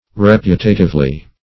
reputatively - definition of reputatively - synonyms, pronunciation, spelling from Free Dictionary Search Result for " reputatively" : The Collaborative International Dictionary of English v.0.48: Reputatively \Re*put"a*tive*ly\ (r?-p?t"?-t?v-l?), adv.
reputatively.mp3